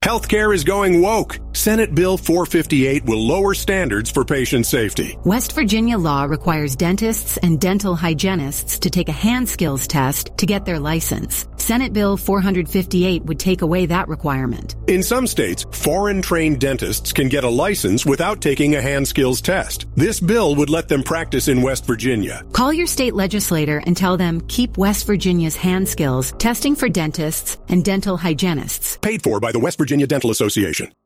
WVDA has launched social media and radio ads to support preserving clinical hand-skills tests for dentists and dental hygienists.